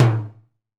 LTIMBALE L1Q.wav